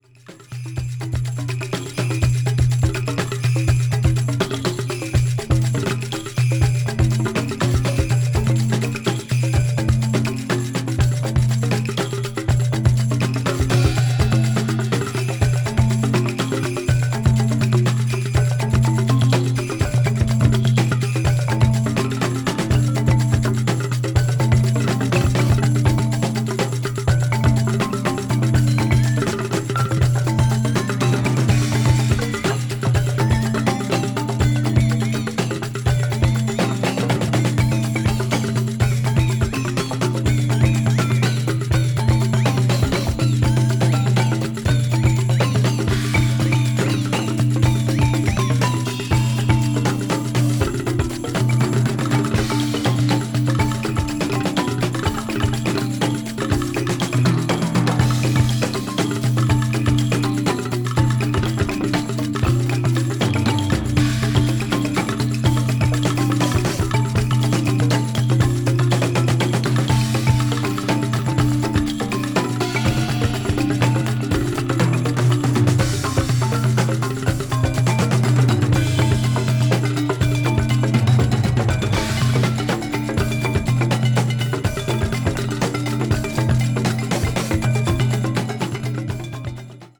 media : EX/EX(わずかにチリノイズが入る箇所あり)
electronic   progressive rock   psychedelic   synthesizer